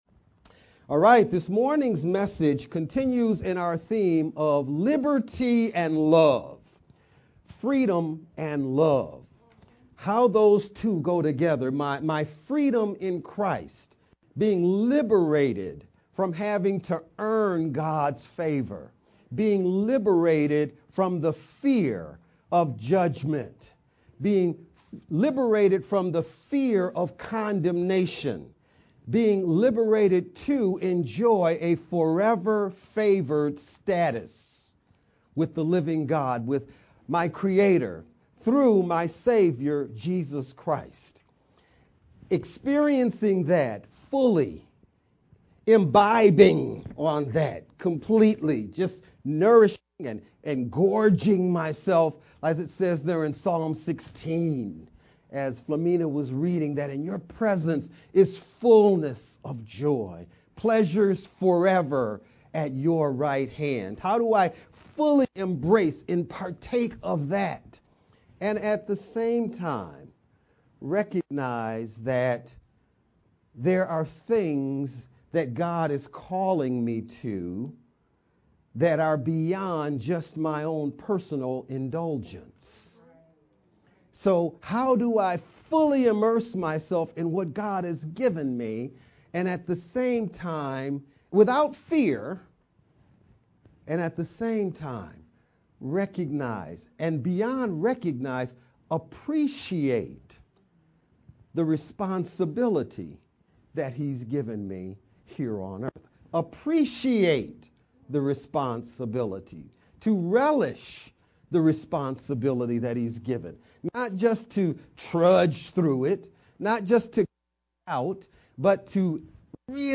VBCC-Sermon-edited-1-8-sermon-only-CD.mp3